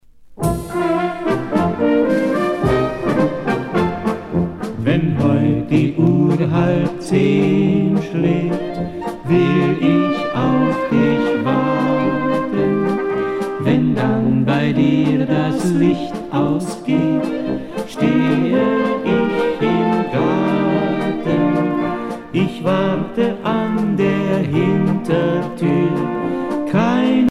Fonction d'après l'analyste danse : marche
Pièce musicale éditée